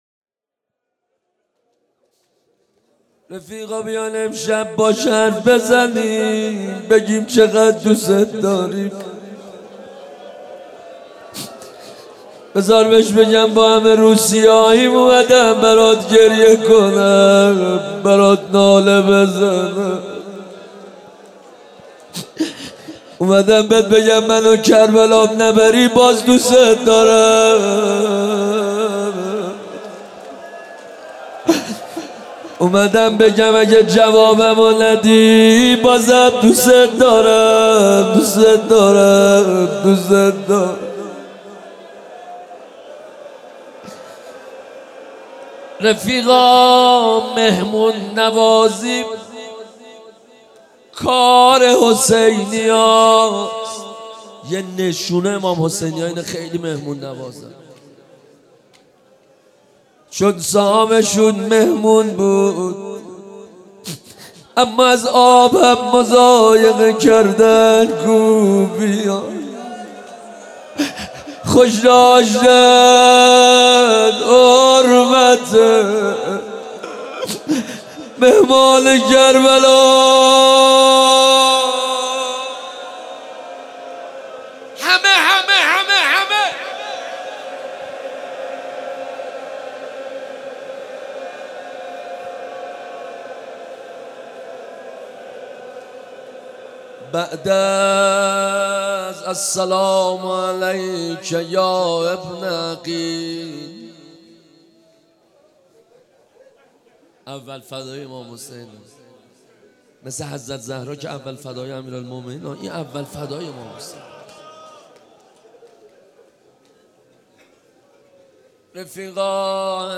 روضه حضرت مسلم